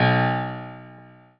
piano-ff-15.wav